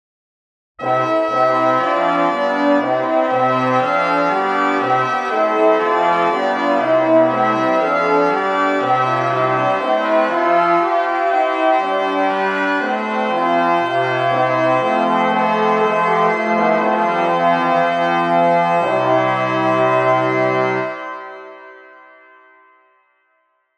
音源は、比較のために、全て金管にしています。